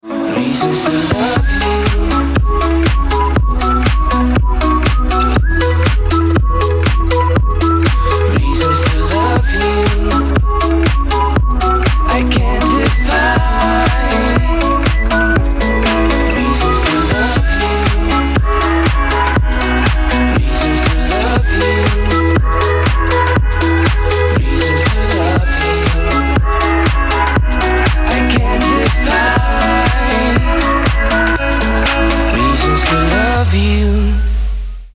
поп
свист
мужской вокал
dance
whistle
Whistling